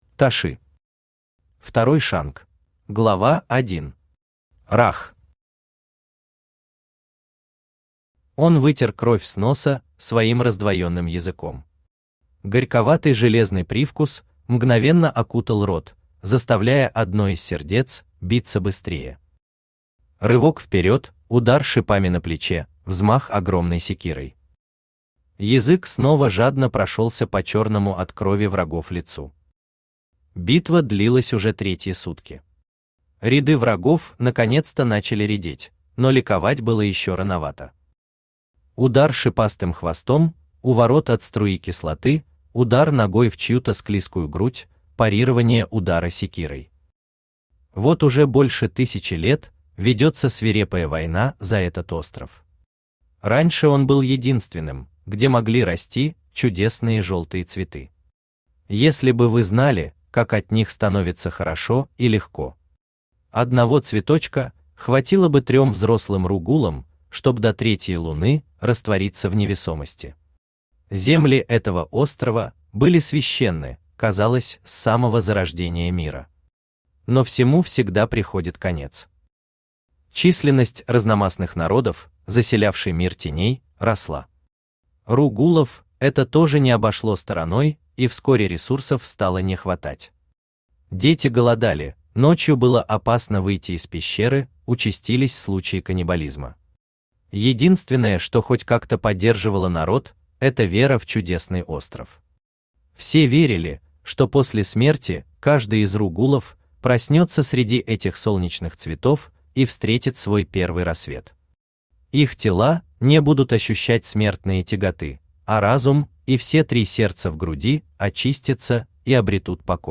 Aудиокнига Второй шанc Автор Toshi Читает аудиокнигу Авточтец ЛитРес.